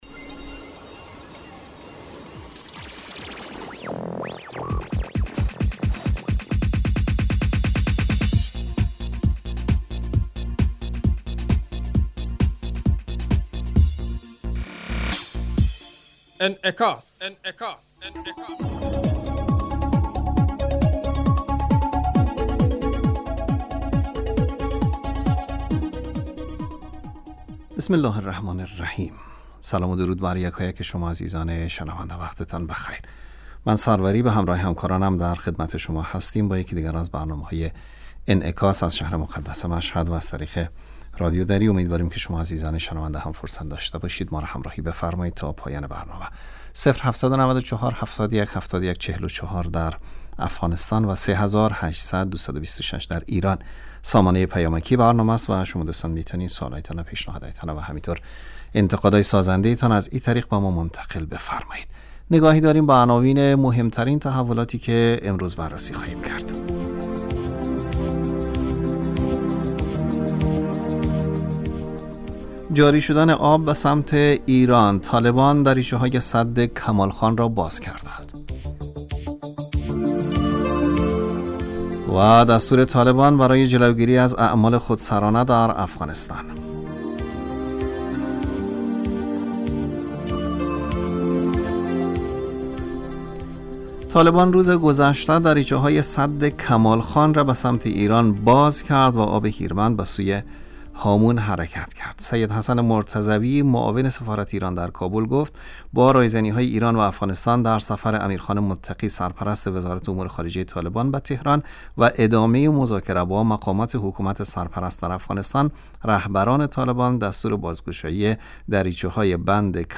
برنامه انعکاس به مدت 30 دقیقه هر روز در ساعت 12:15 ظهر (به وقت افغانستان) بصورت زنده پخش می شود.